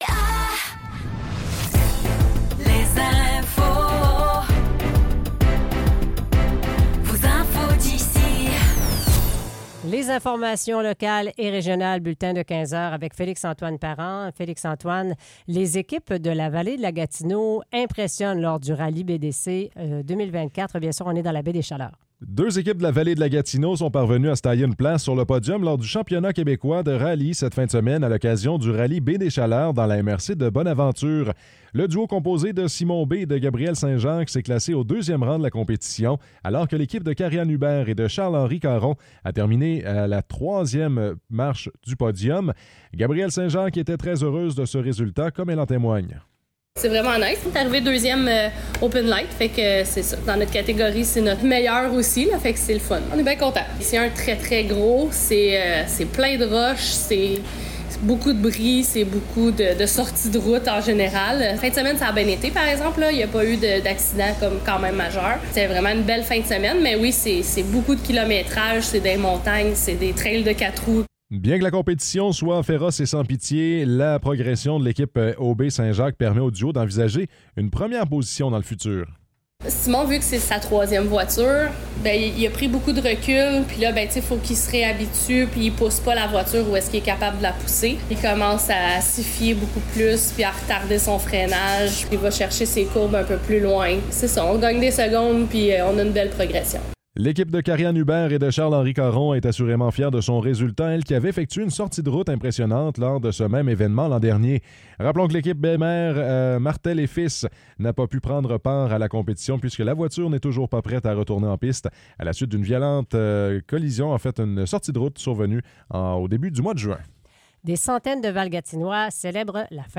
Nouvelles locales - 2 juillet 2024 - 15 h